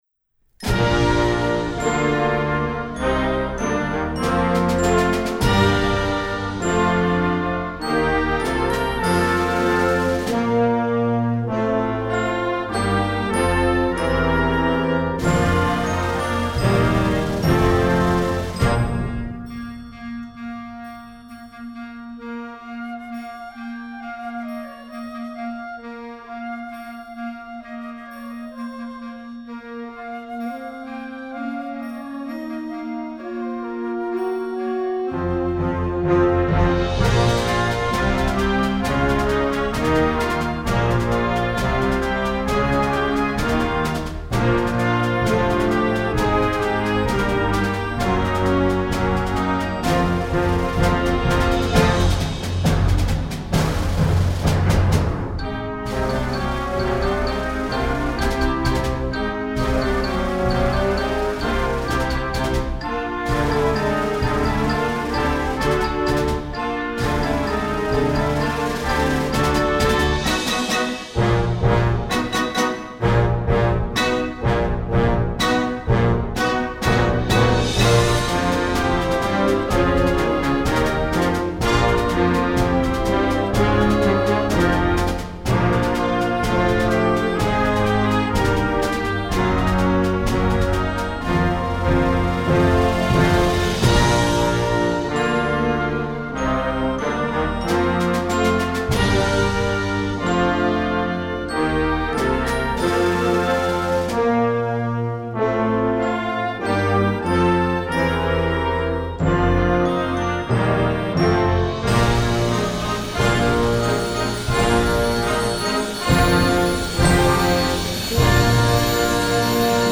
Instrumentation: concert band
instructional